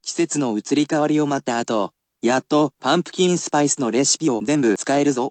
I will also read this aloud, but it is usually at regular speed, and is usually very specific, so you need not repeat if it is too fast.
[emphatic casual speech]